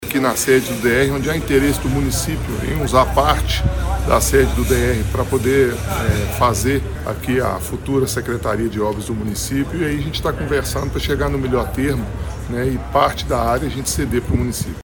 O diretor-geral do (DER-MG), Rodrigo Rodrigues Tavares afirmou que as conversas estão muito avançadas e que o Estado de Minas Gerais deve ceder boa parte do imóvel do DER-MG ao município de Pará de Minas: